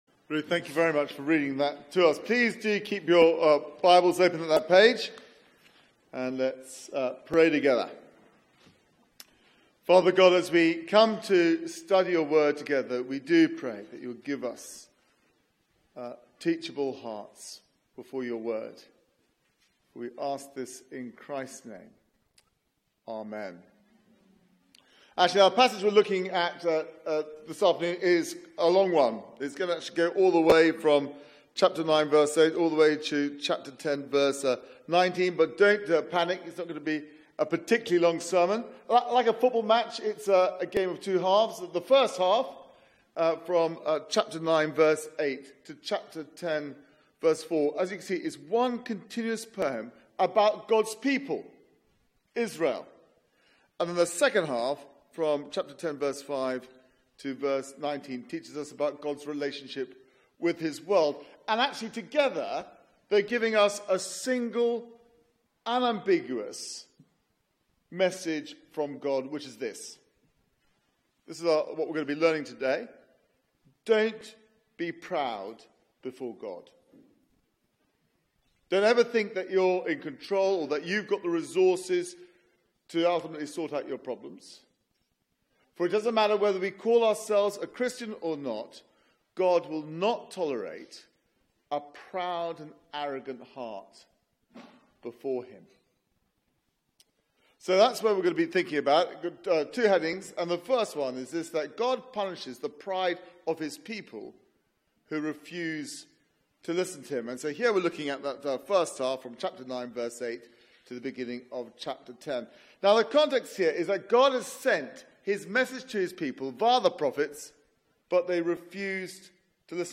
Media for 4pm Service on Sun 26th Nov 2017 16:00 Speaker